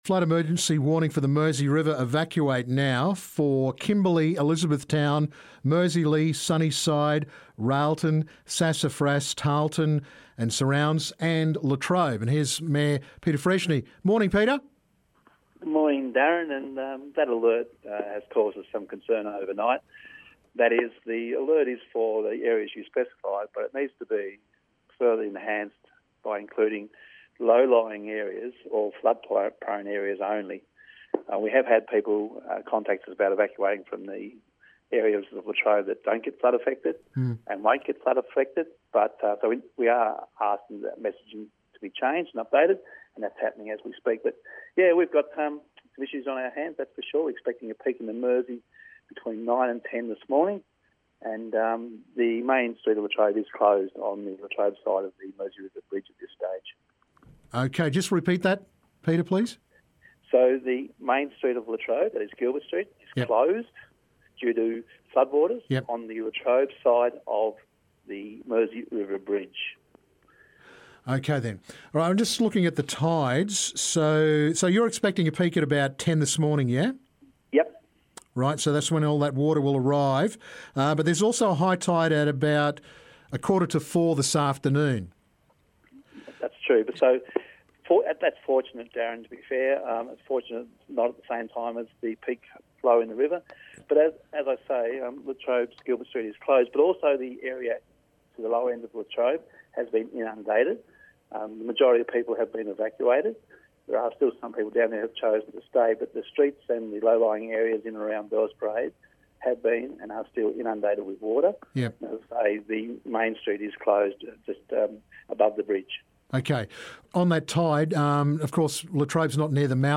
Flood Emergency for Liena to Latrobe. Mayor Peter Freshney has info and advice.